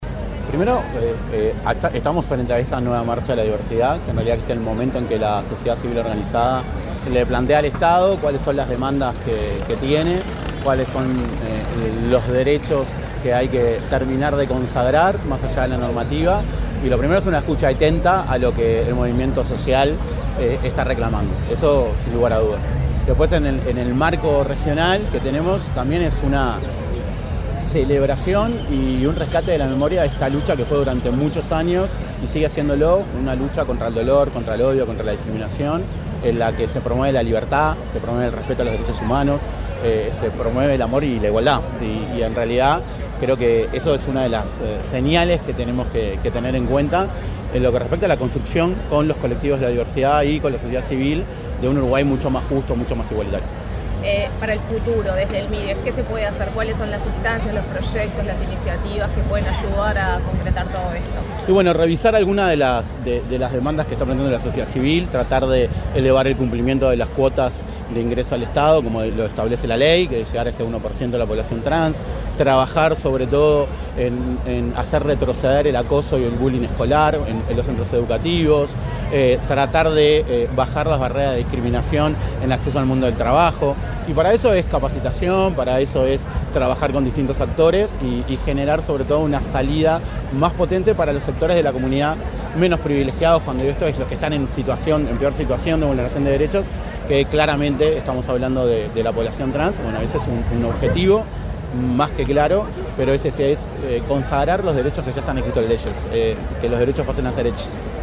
Declaraciones del subsecretario de Desarrollo Social, Federico Graña
Declaraciones del subsecretario de Desarrollo Social, Federico Graña 26/09/2025 Compartir Facebook X Copiar enlace WhatsApp LinkedIn En ocasión de la Marcha de la Diversidad 2025, el subsecretario de Desarrollo Social, Federico Graña, dialogó con la prensa en el punto de partida de la movilización.